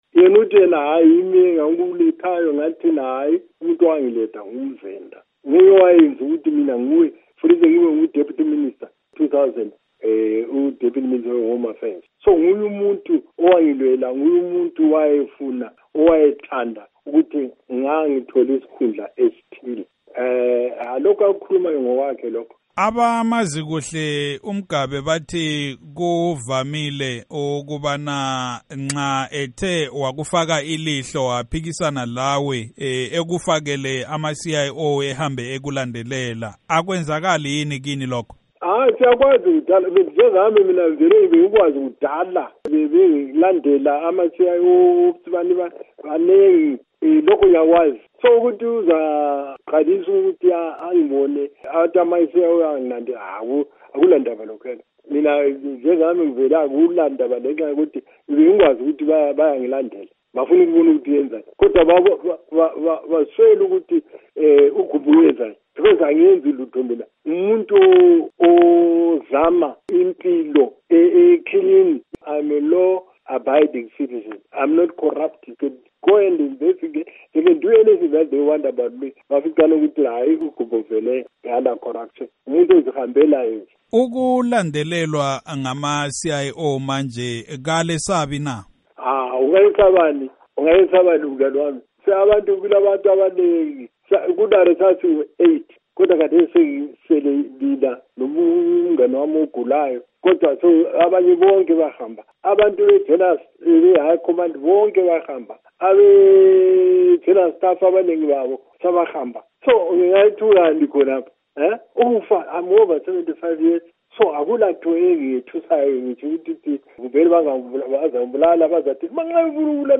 Ingxoxo loMnu Rugare Gumbo